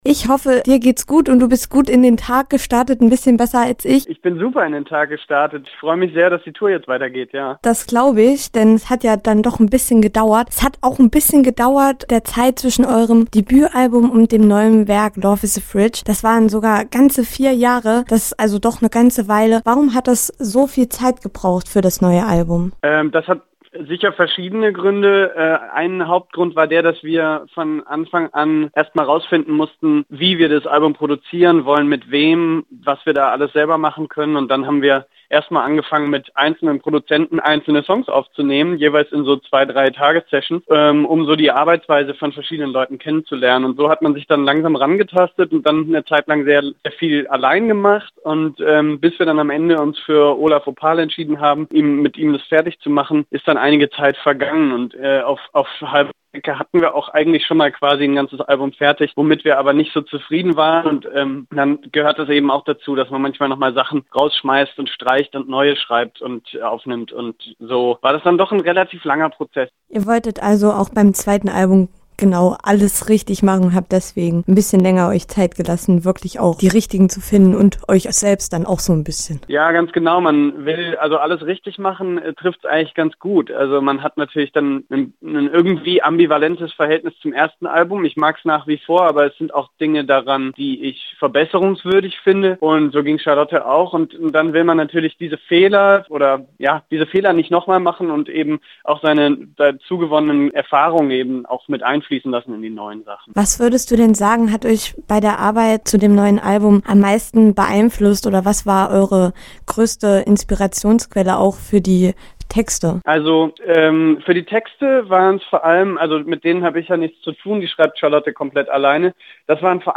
Interview: Me and My Drummer – Campusradio Jena